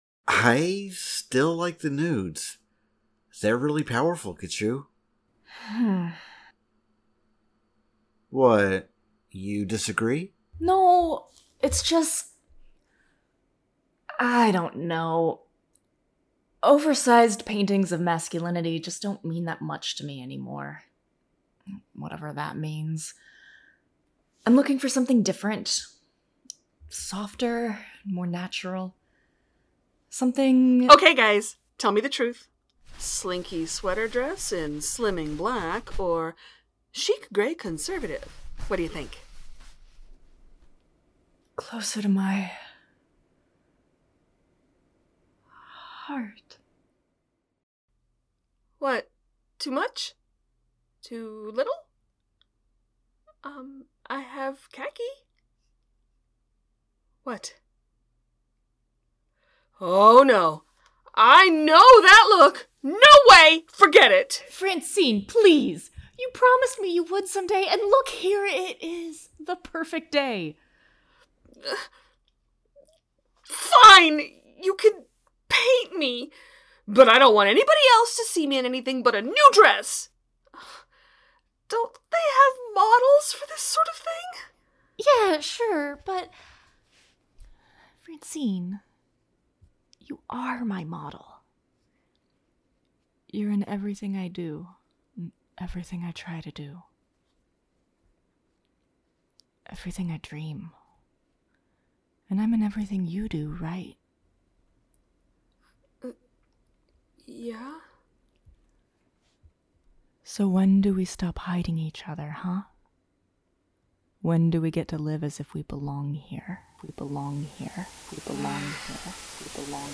strangers-in-paradise-the-audio-drama-book-7-episode-5